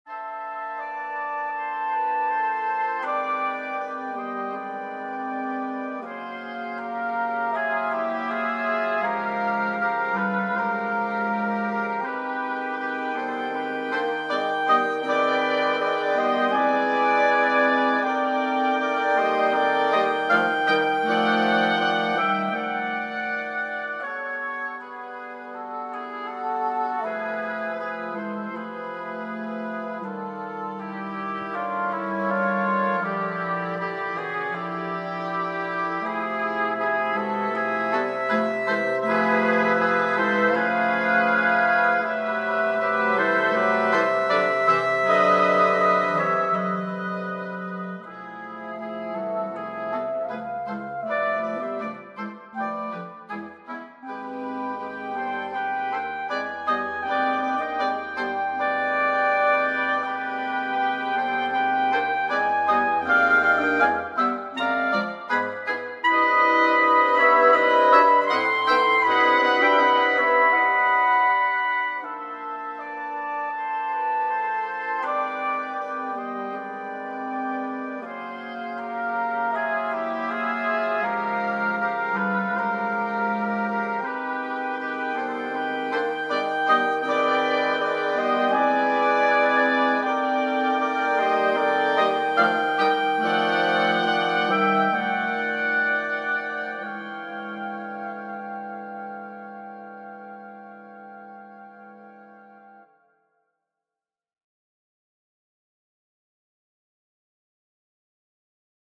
Persichetti Exercise 7 - 50 for Woodwind Sextet